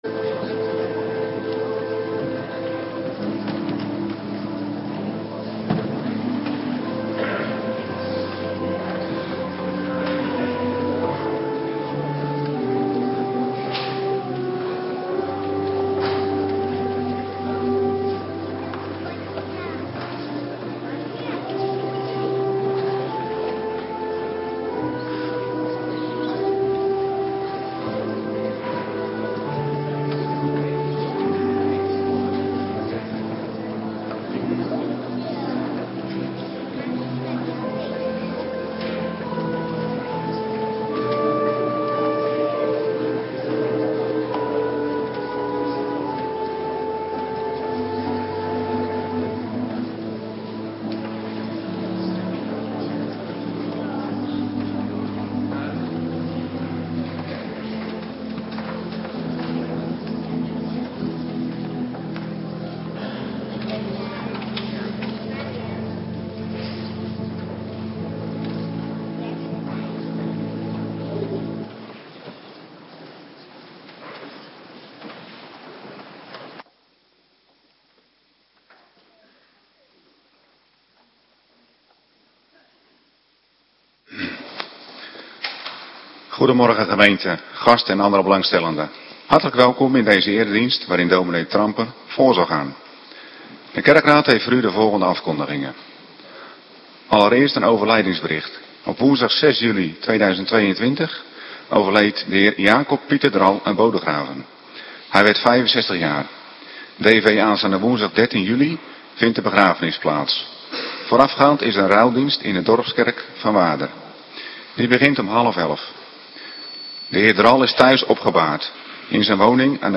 Morgendienst Bed H Doop